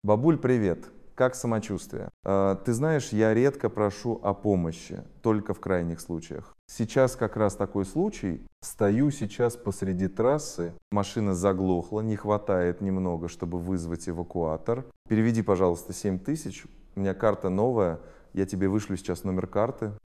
2. Неестественный эмоциональный тон
Синтезированные голоса часто звучат монотонно или, наоборот, выдают внезапные эмоциональные скачки.